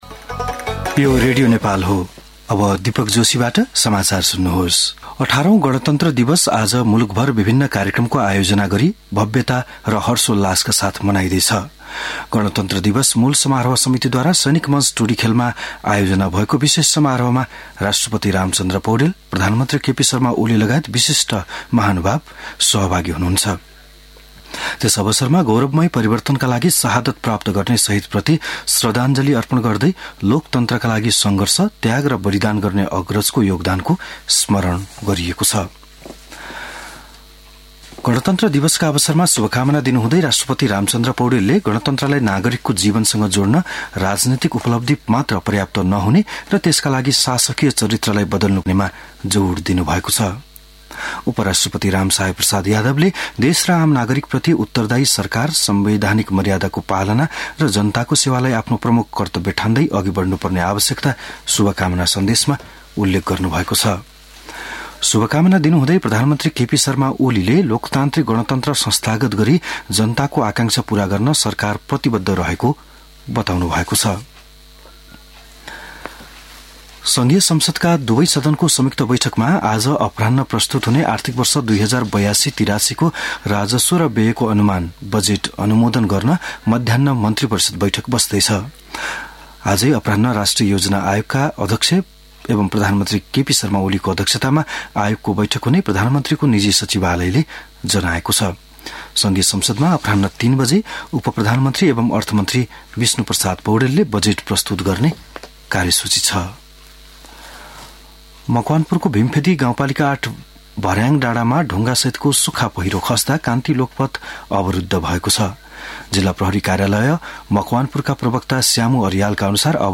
बिहान ११ बजेको नेपाली समाचार : १५ जेठ , २०८२